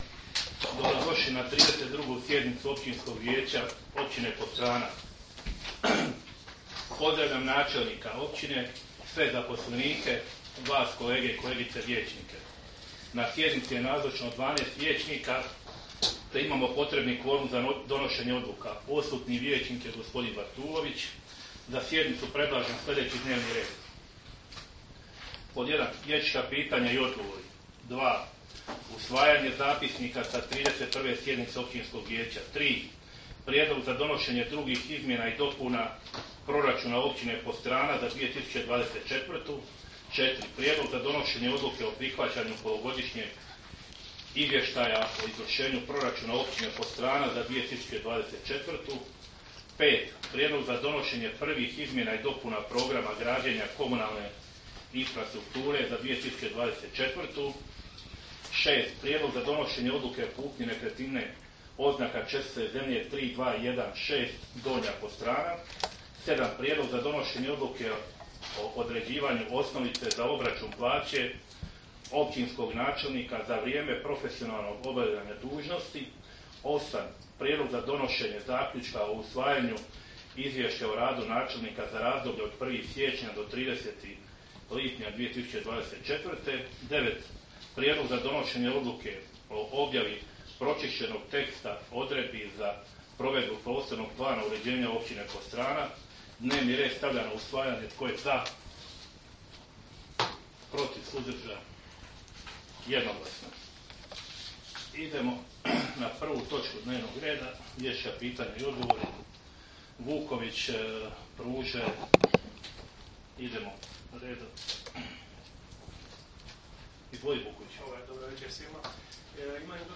Sjednica će se održati dana 25. rujna (srijeda) 2024. godine u 19,00 sati u Vijećnici Općine Podstrana.